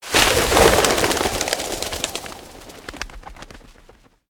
birdsfear1.ogg